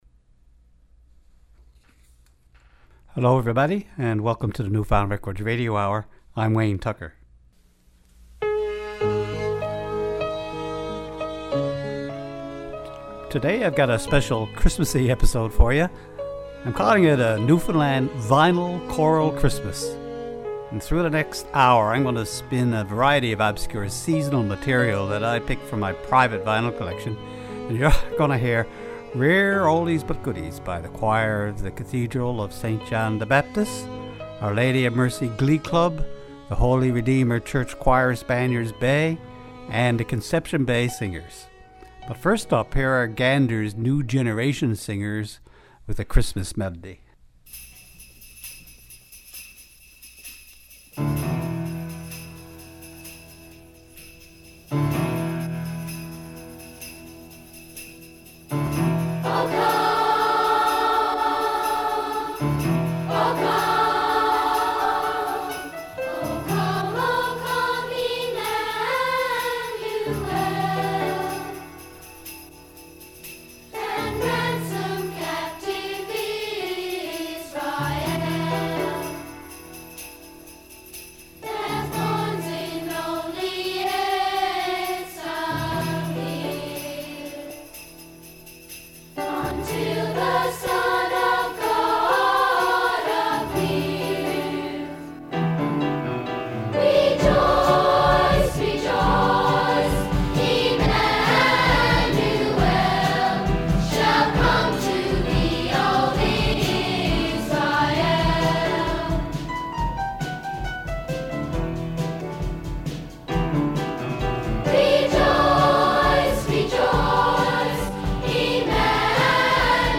Recorded at CHMR Studios, MUN, St. John's, NL.
NF_Records_Choral_Christmas.mp3